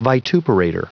Prononciation du mot vituperator en anglais (fichier audio)
vituperator.wav